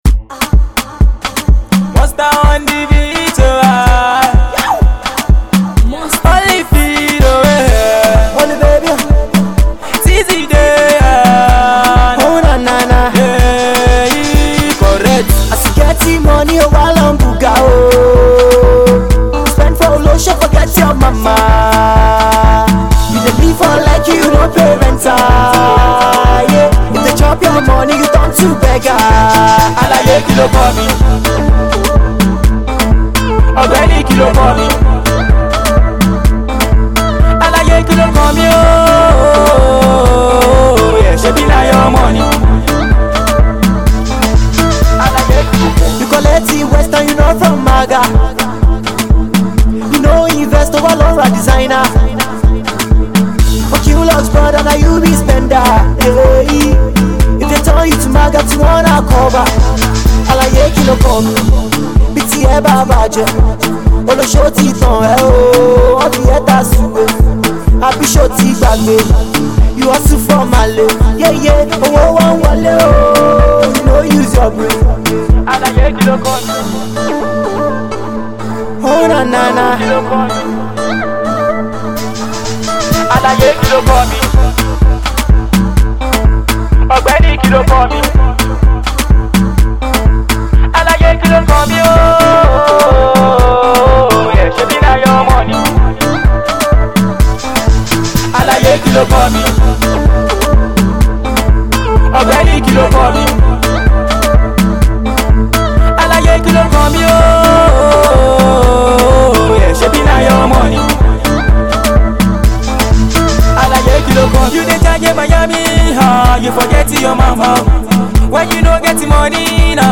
dance hall track